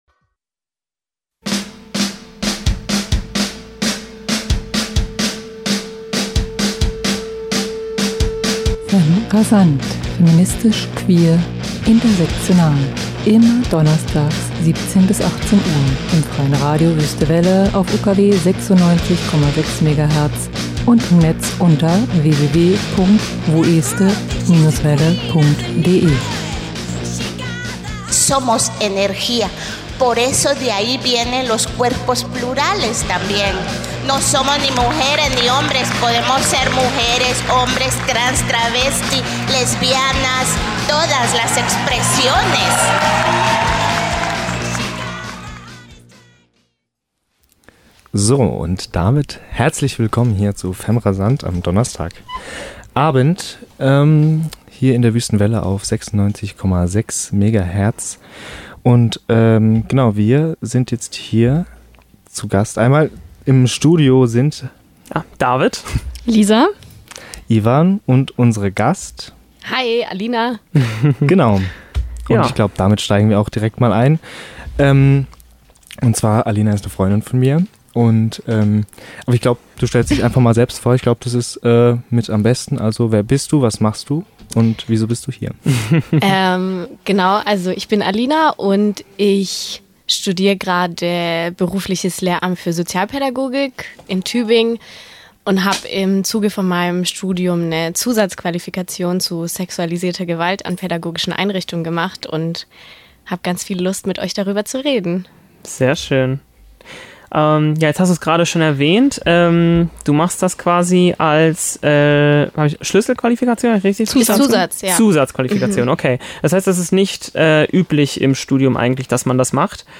*TRIGGERWARNUNG* In diesem Interview geht es um sexualisierte Gewalt in pädagogischen Einrichtungen-